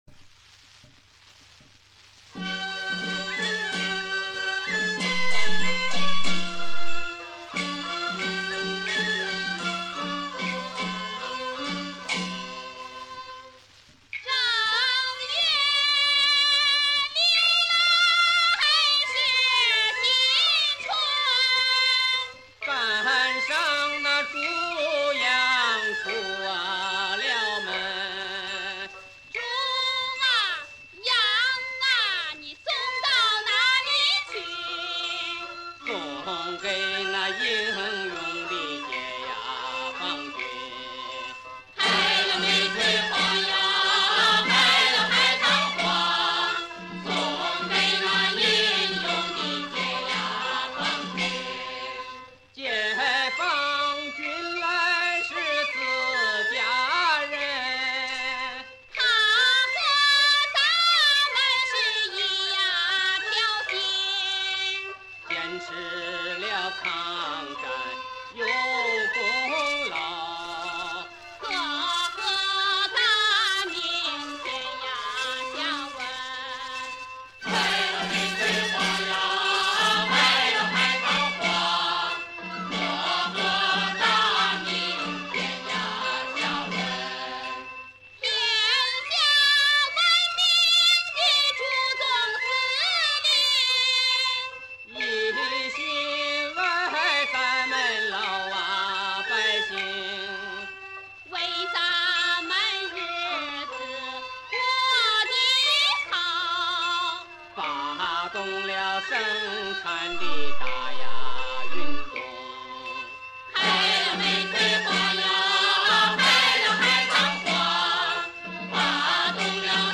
陕北民歌